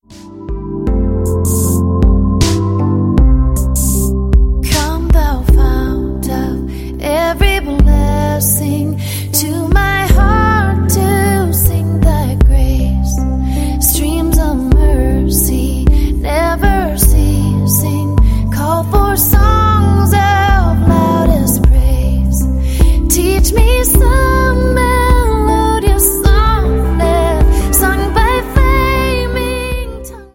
Your favorite Hymns - Set to a Vibey, Chillout Beat
• Sachgebiet: Dance